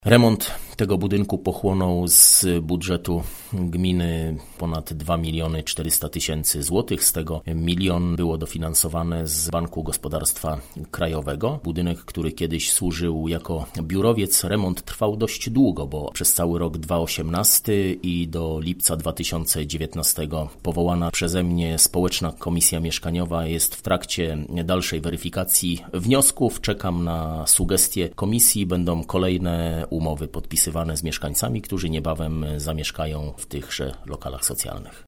– Na trzech pozostałych kondygnacjach znajduje się piętnaście mieszkań jedno i dwupokojowych – informuje Mariusz Olejniczak, burmistrz Słubic.